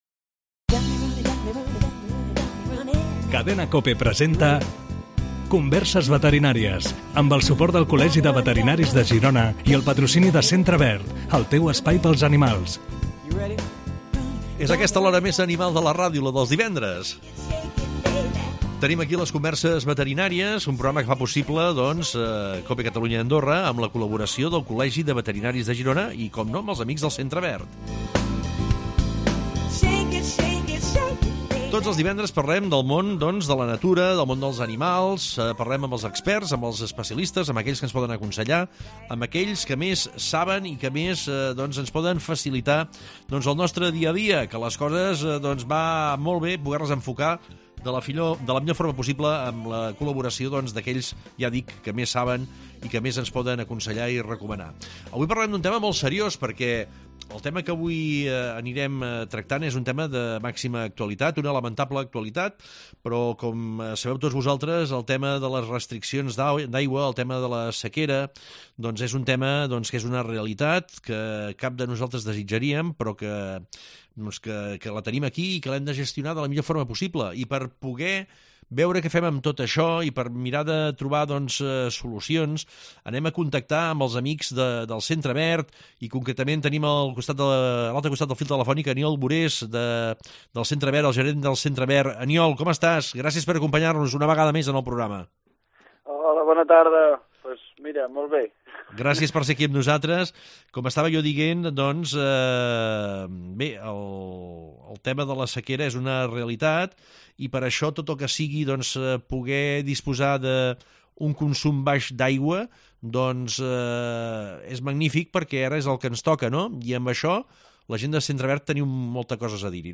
Converses